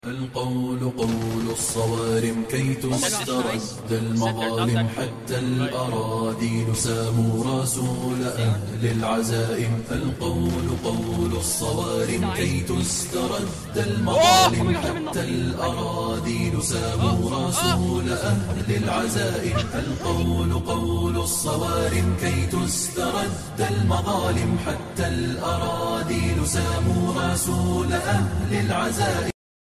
arena breakout clips with nasheed sound effects free download